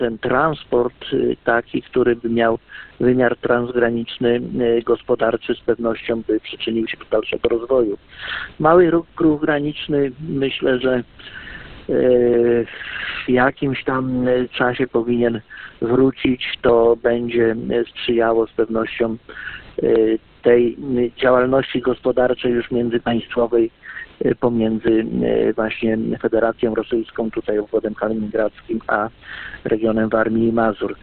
Wspólny głos za koleją ma pomóc w zaplanowaniu tej inwestycji na szczeblu rządowym. O potrzebie takich działań dla naszego regionu mówi Radiu 5 Gustaw Marek Brzezin, Marszałek Województwa Warmińsko-Mazurskiego.